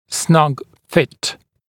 [snʌg fɪt][снаг фит]плотное прилегание